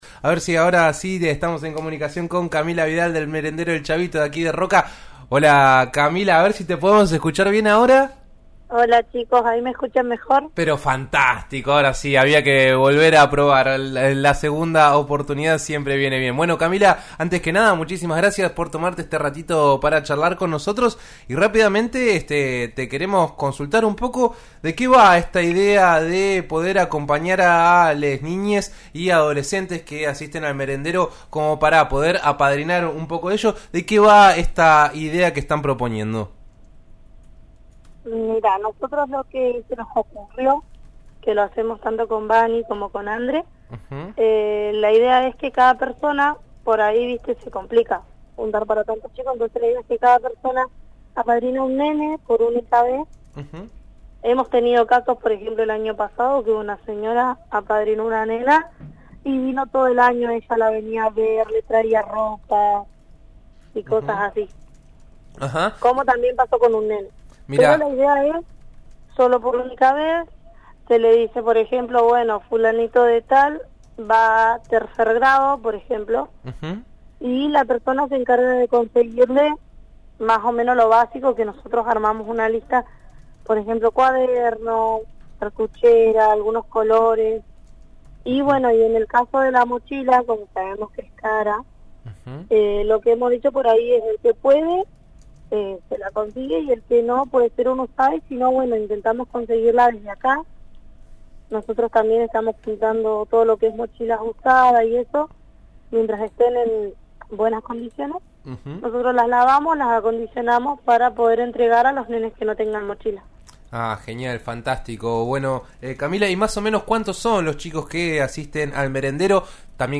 al aire de En Eso Estamos